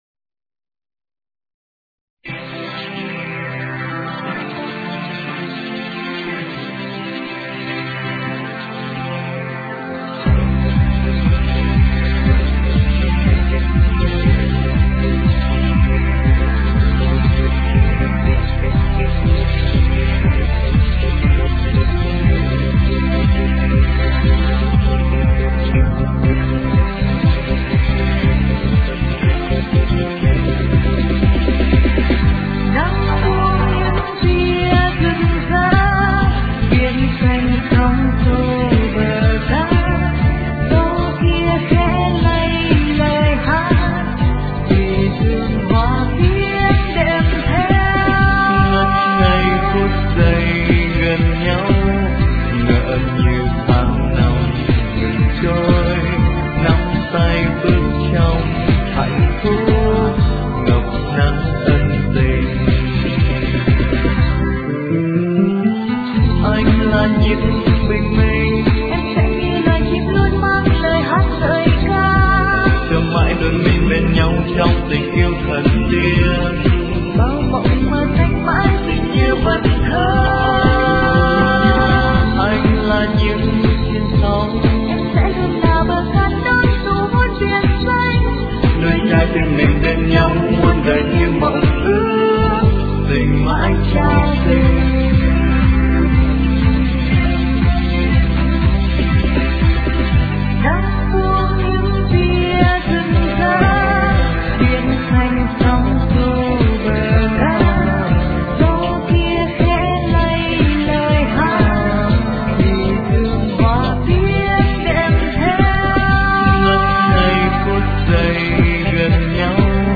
Được thể hiện qua giọng hát của ca sĩ
* Thể loại: Nhạc Việt